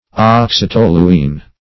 Search Result for " oxytoluene" : The Collaborative International Dictionary of English v.0.48: Oxytoluene \Ox`y*tol"u*ene\, n. [Oxy (a) + toluene.] One of three hydroxy derivatives of toluene, called the cresols.